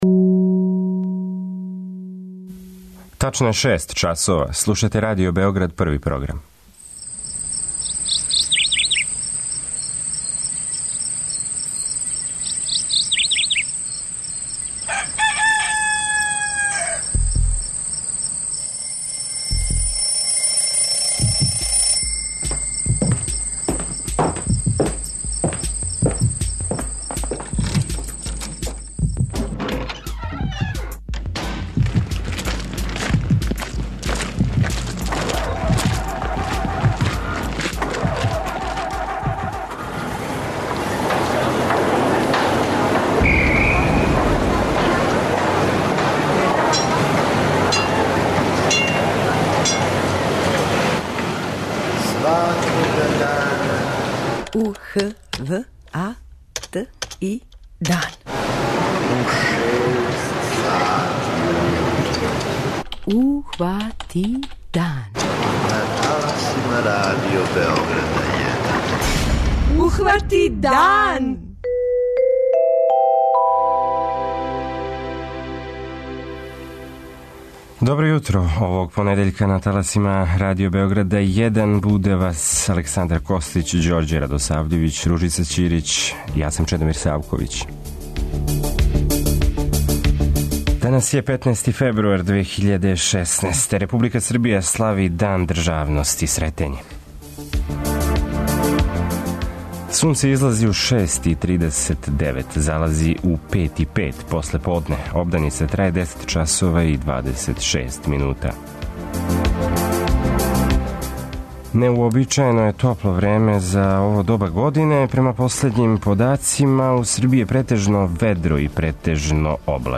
преузми : 85.96 MB Ухвати дан Autor: Група аутора Јутарњи програм Радио Београда 1!